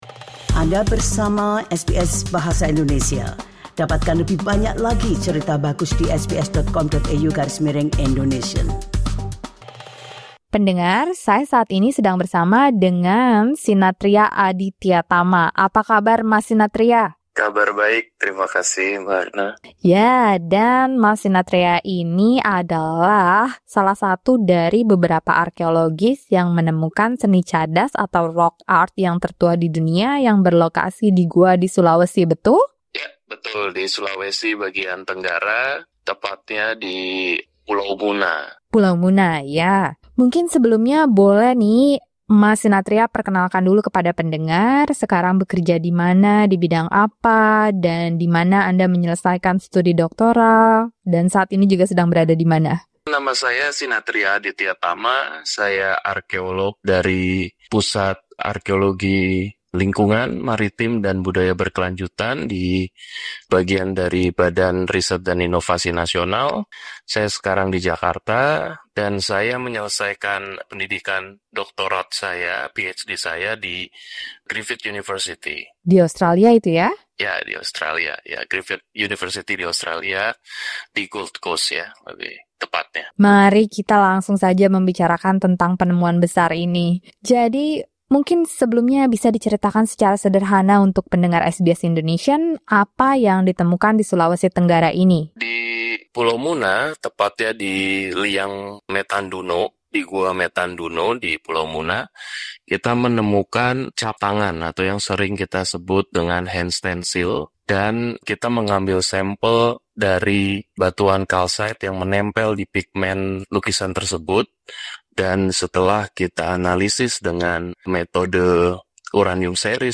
In an exclusive interview with SBS Indonesia